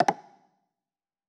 Wood Block3.wav